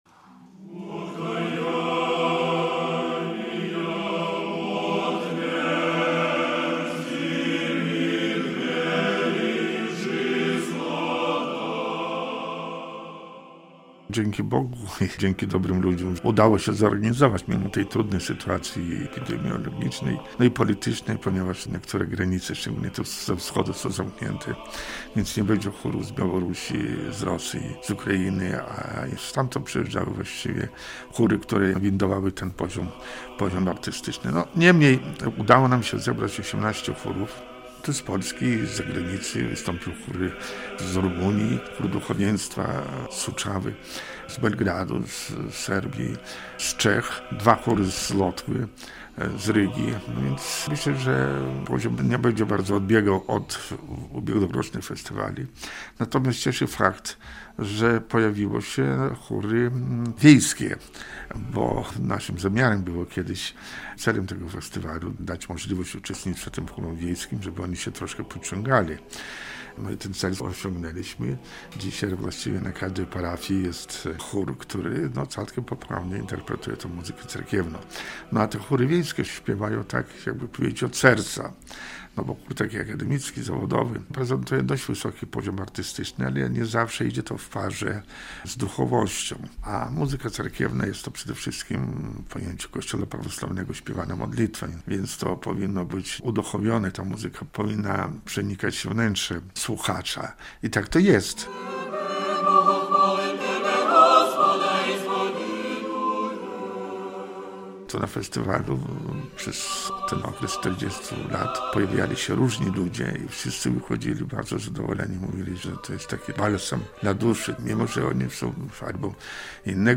O festiwalu opowiada ksiądz